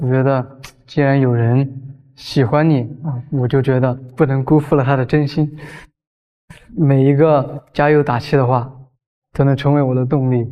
Voce maschile romantica profonda per audiodrammi e romanzi
Voce maschile romantica coinvolgente
Affascina il tuo pubblico con una voce AI calda e intima progettata per romanzi d'amore, audiodrammi e narrazioni emozionanti.
Text-to-Speech
Risonanza profonda